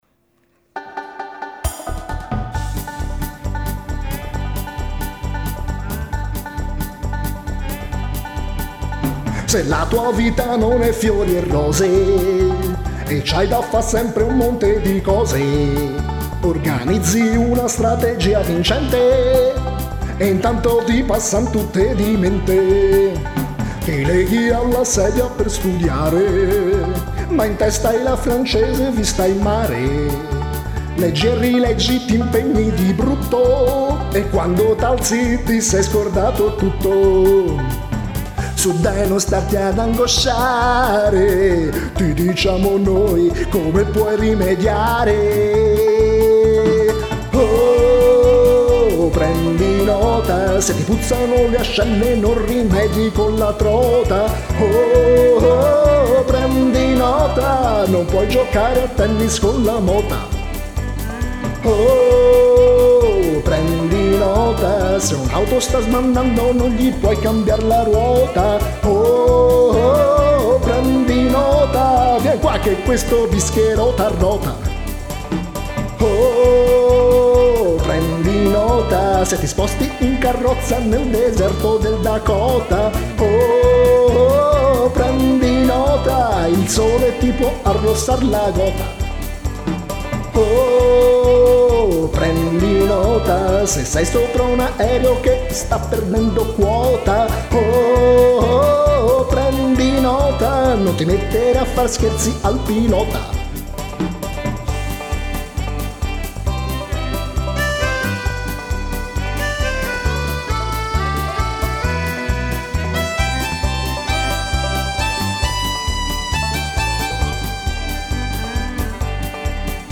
Un brano spensierato e orecchiabile per festeggiare il quarto anniversario del sito.